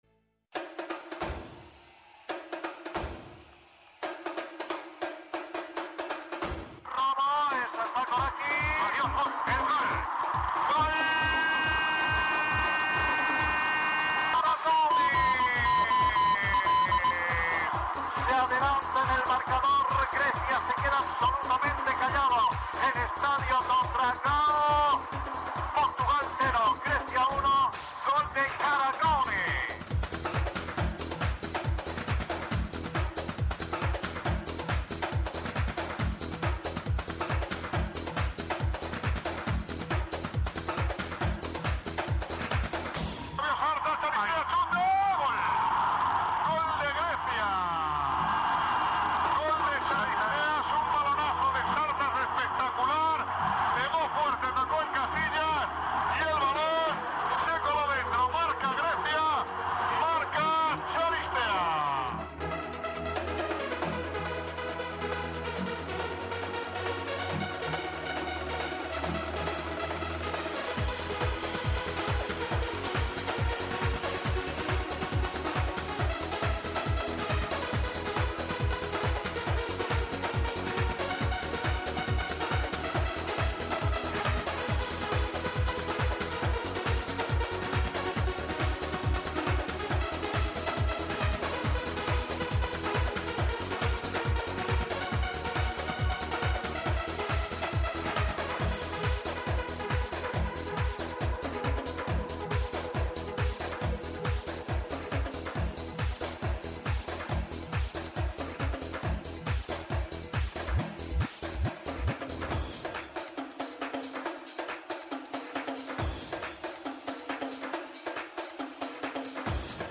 αποκλειστική συνέντευξη